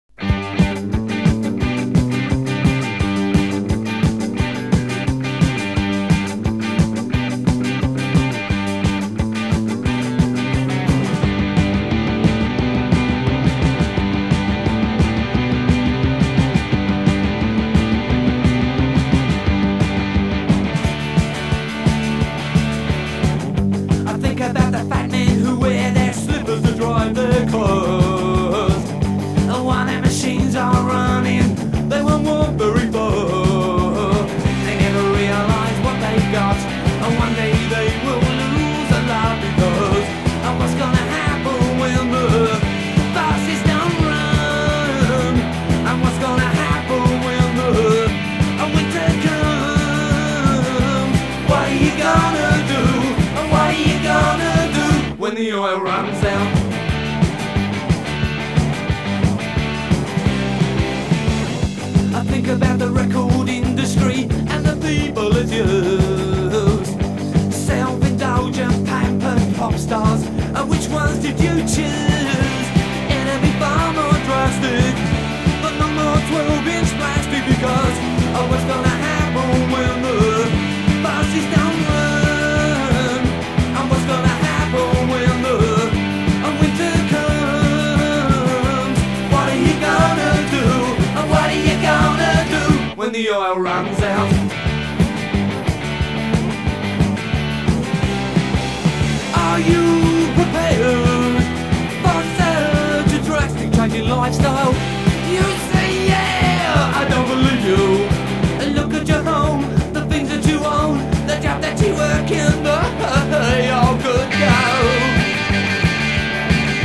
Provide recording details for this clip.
(Higher quality half version)